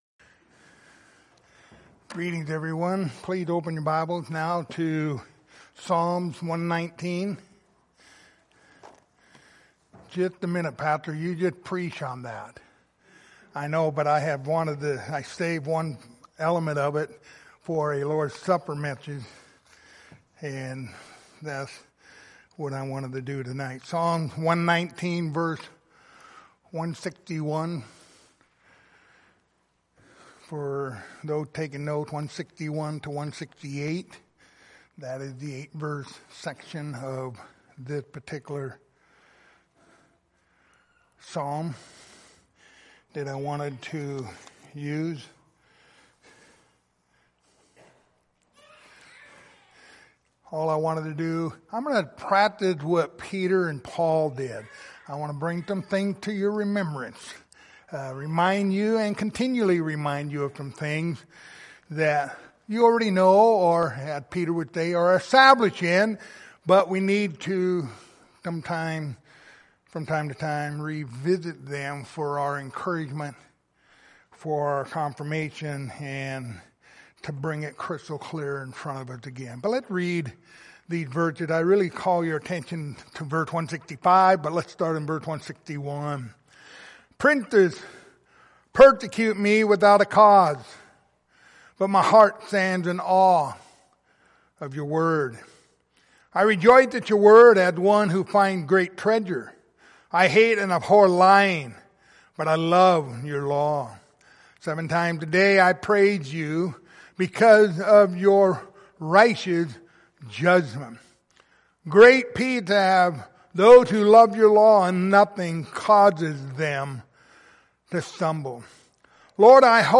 Passage: Psalm 119:161-168 Service Type: Lord's Supper